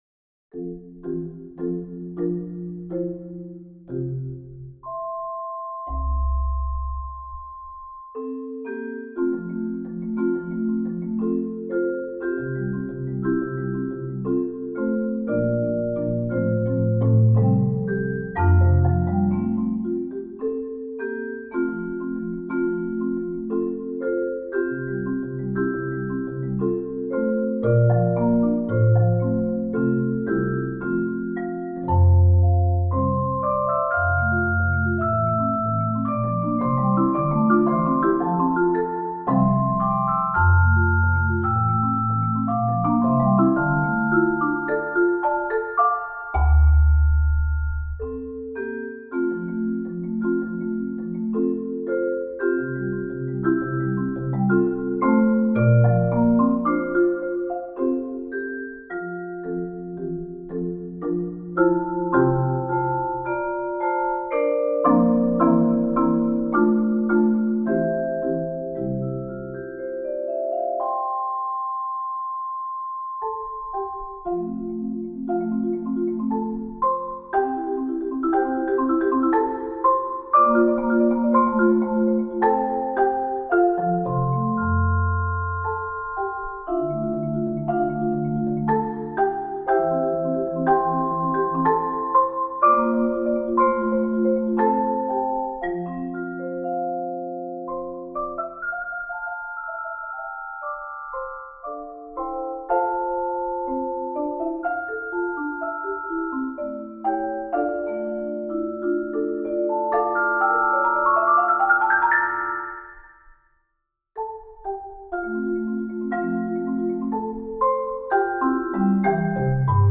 Voicing: Mallet Duet